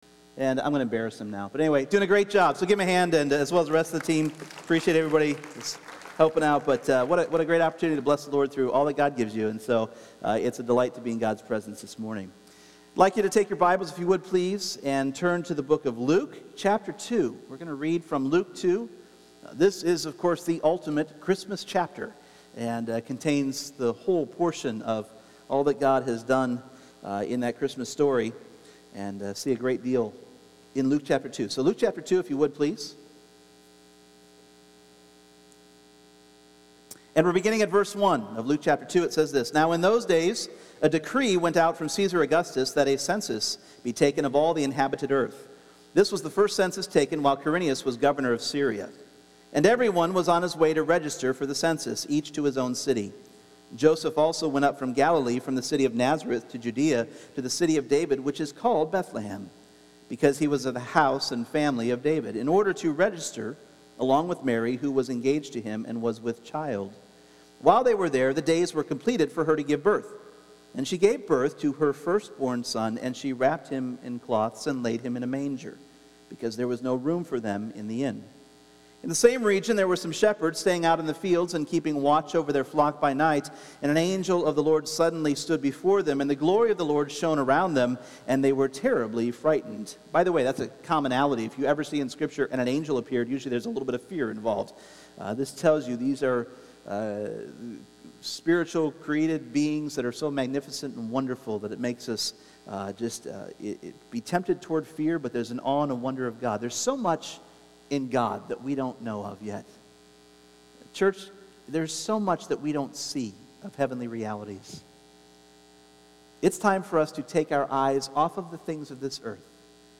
Ephesians 6:10-17 Service Type: Sunday Morning What is it about the Shield of Faith that EXTINGUISHES the flaming arrows of the enemy?